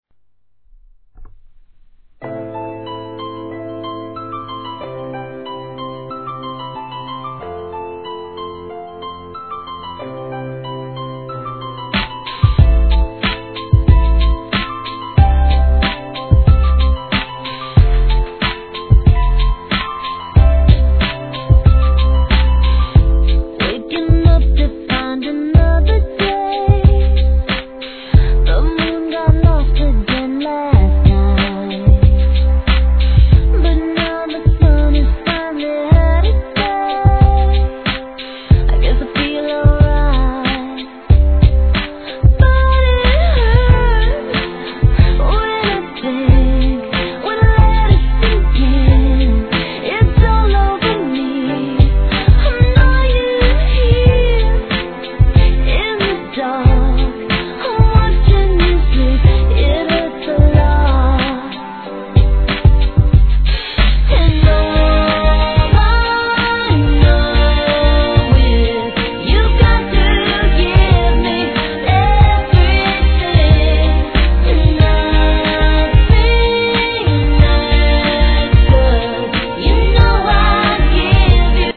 HIP HOP/R&B
POP色の強いミッドチューン!!!